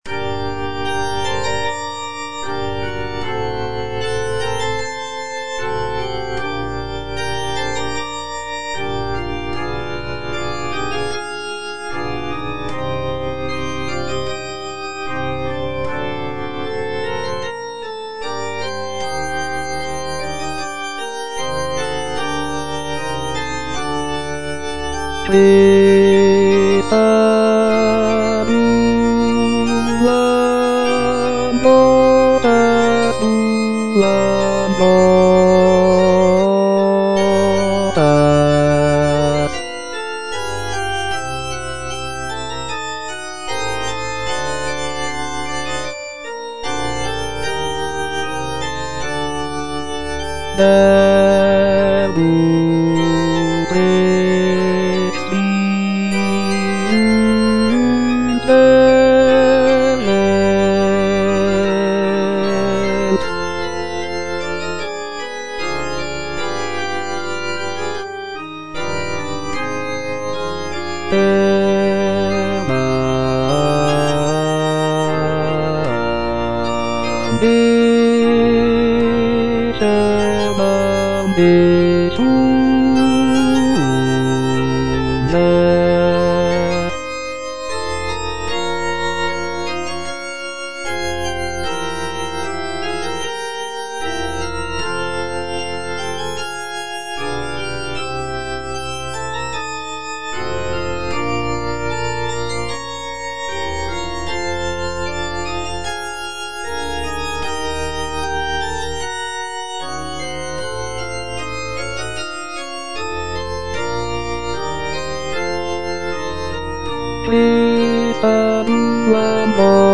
Bass (Voice with metronome) Ads stop